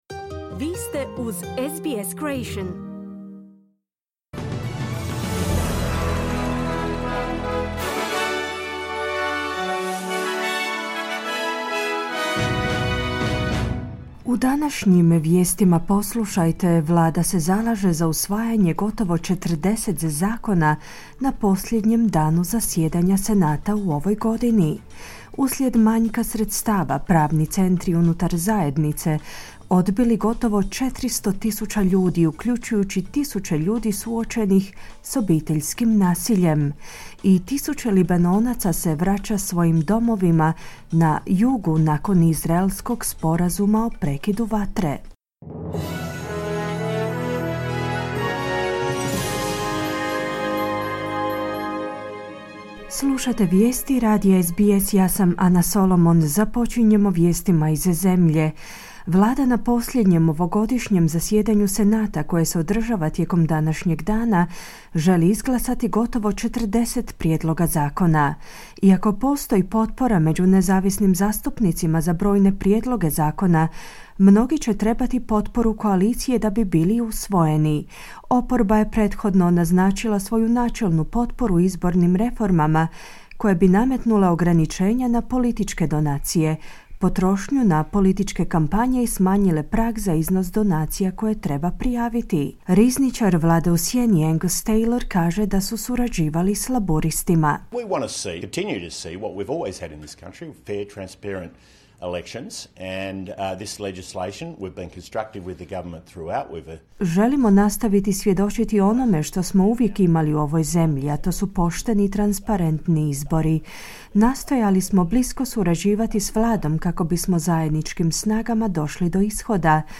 Vijesti radija SBS na hrvatskom jeziku.